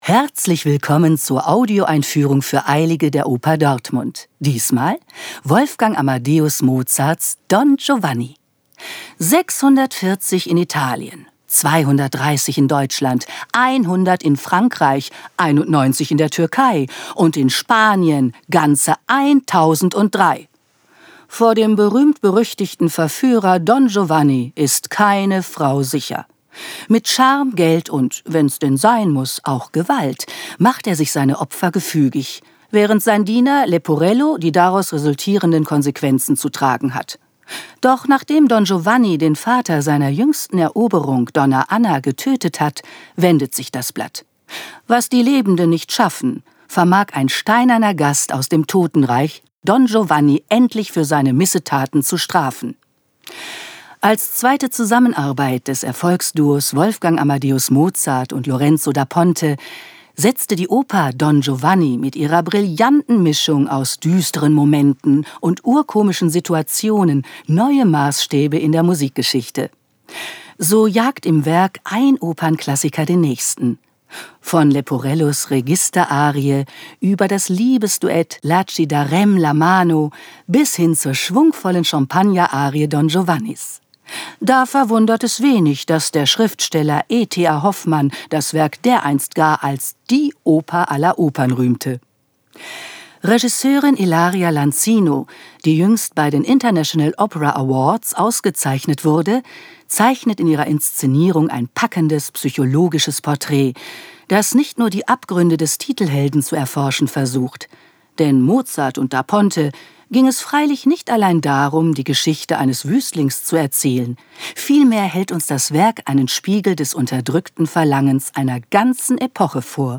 tdo_Audioeinfuehrung_Don_Giovanni.mp3